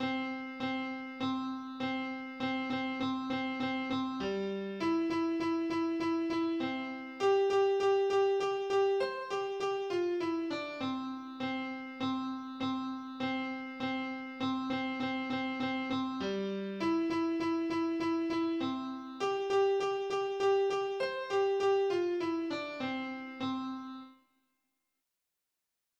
4 iskua ennen laulua alkua